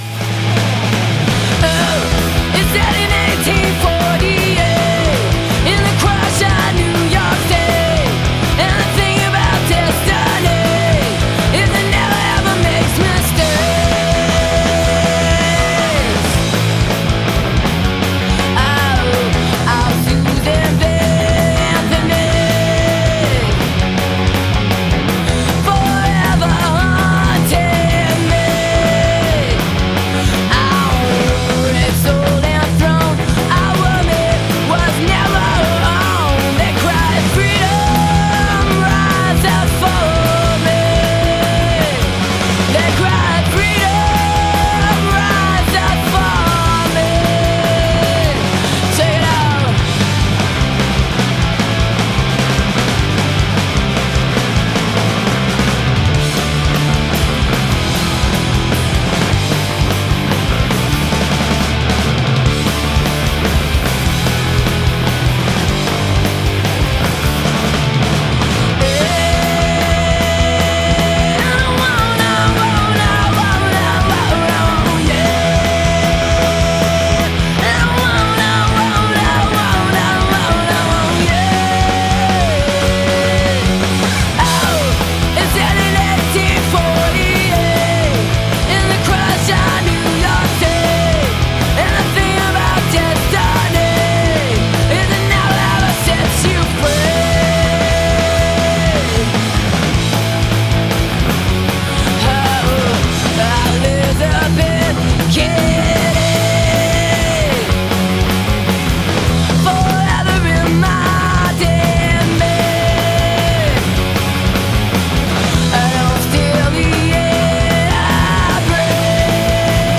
(captured from webcast)
album version